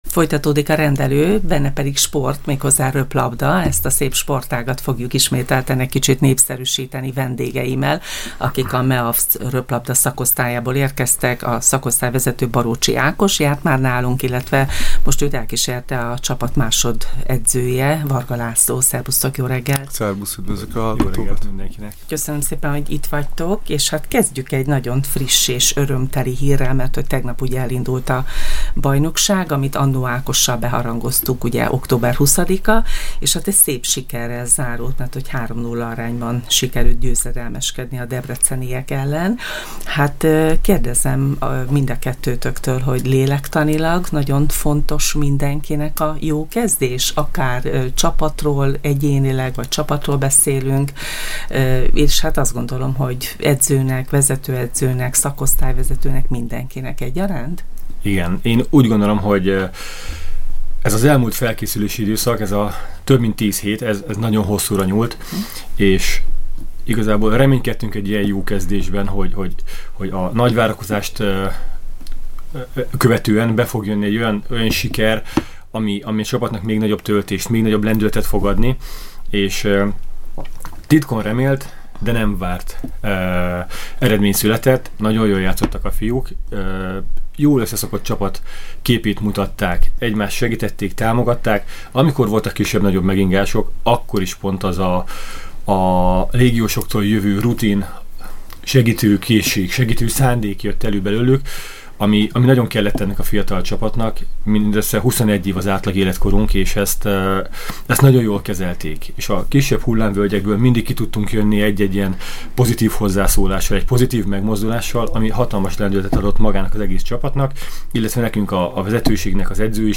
A 3:0 arányú Debrecen elleni győztes meccs után beszélgettünk vendégeinkkel